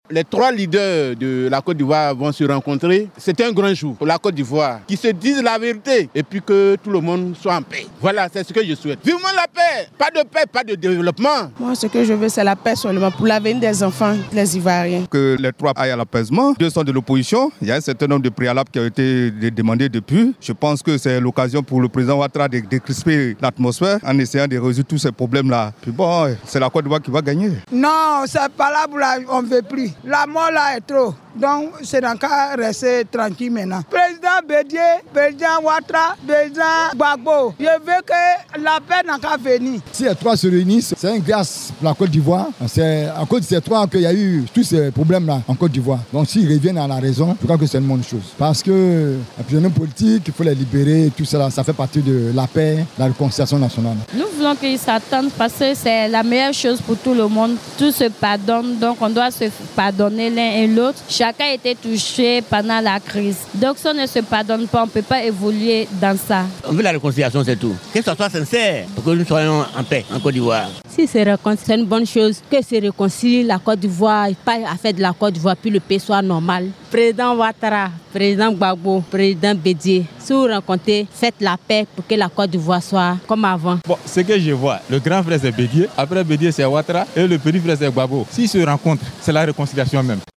vox-pop-la-rencontre-annoncee-ouattara-bedie-gbagbo-du-14-juillet.mp3